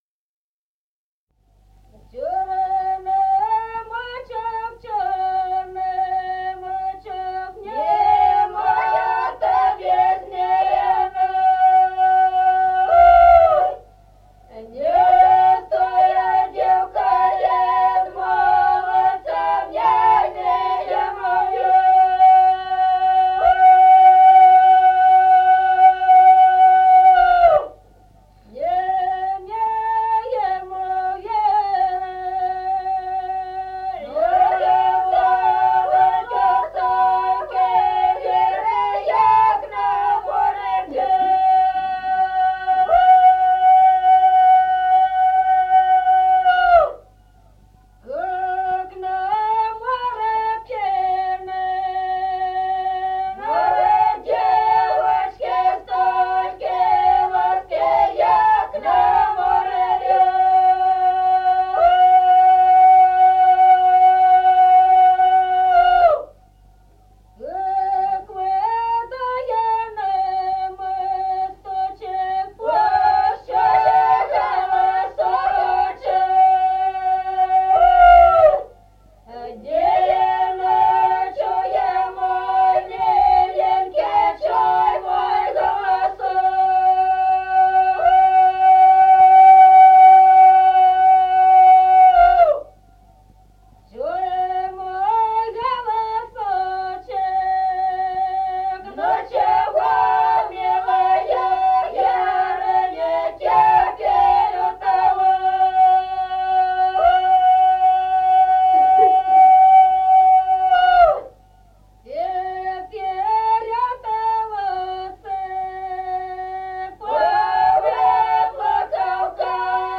Песни села Остроглядово. Чёрный мачок (петровочная).